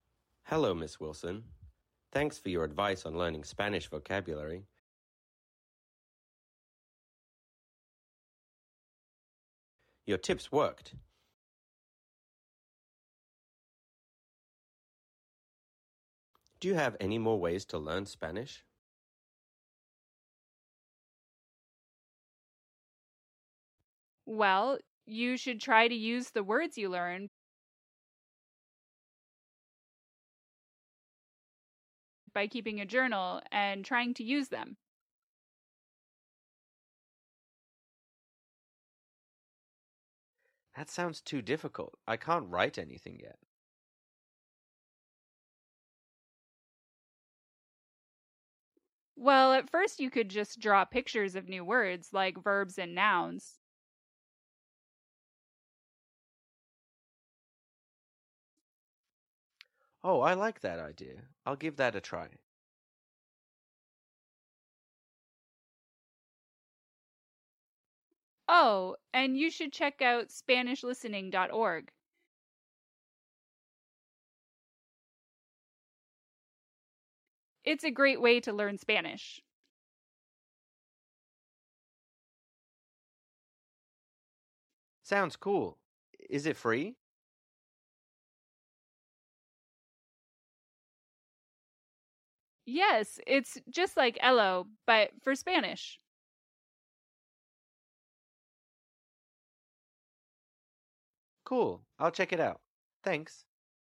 TRAIN - Conversation 4 - More Ways to Learn Spanish